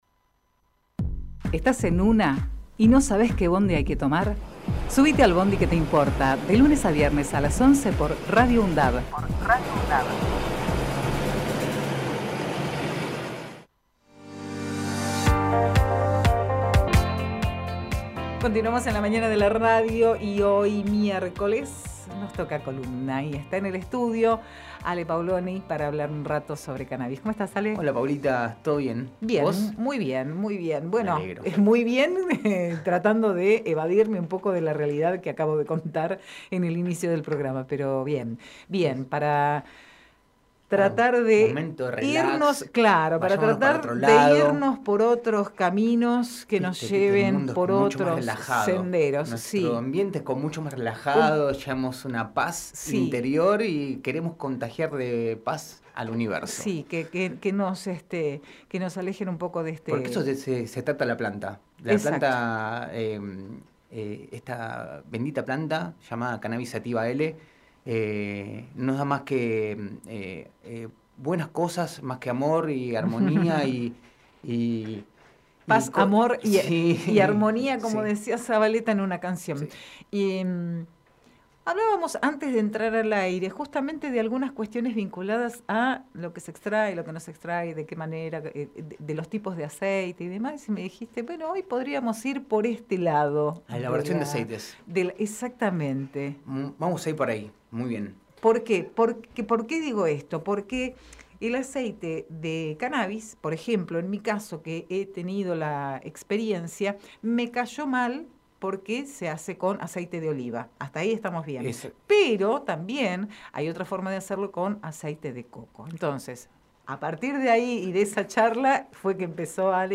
Compartimos entrevista